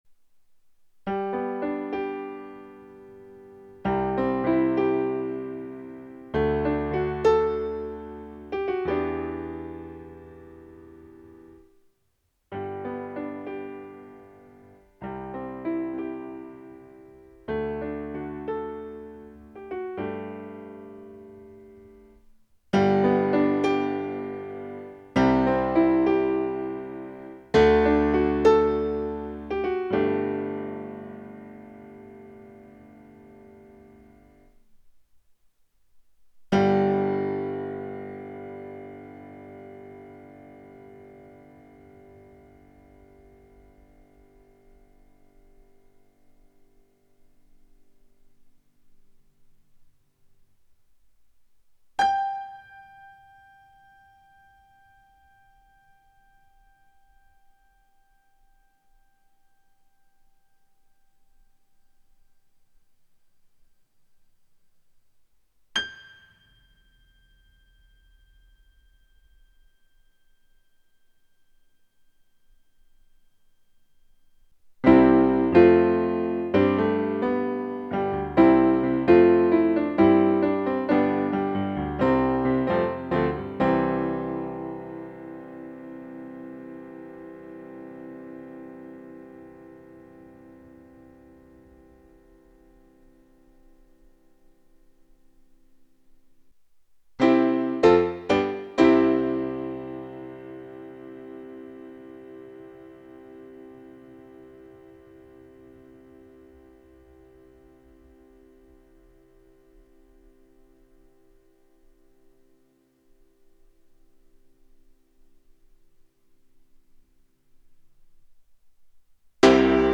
DELAMAR SCORE 4.0 Thomann DP-51 Digitalpiano Test Unter dem Strich Günstiges Digitalpiano mit kräftigem Lautsprechersystem.
thomann_dp_51_test__40_sounds.mp3